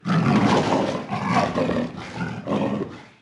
دانلود صدای پلنگ برای کودکان از ساعد نیوز با لینک مستقیم و کیفیت بالا
جلوه های صوتی